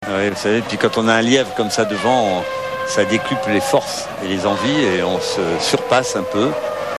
sur TF1